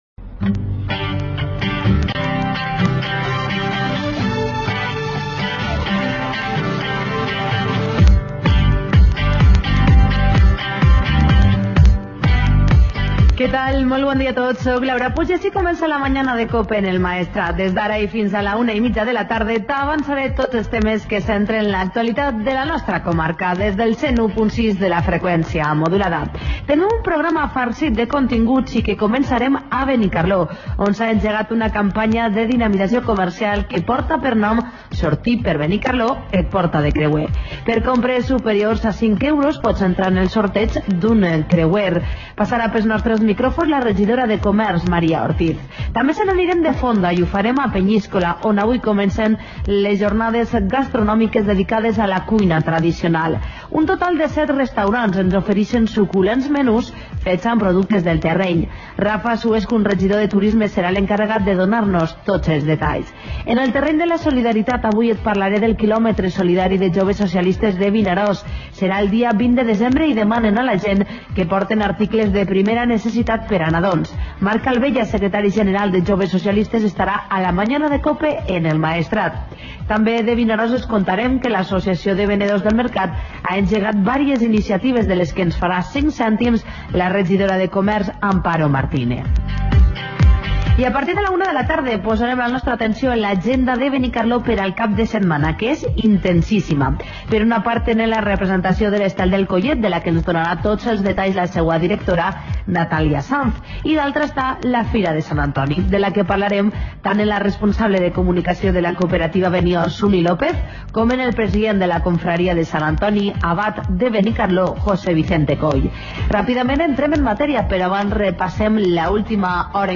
Magazine de COPE Vinaròs para toda la zona del Maestrat